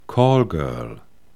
Aussprache:
🔉[ˈkɔːlɡœːɐ̯l]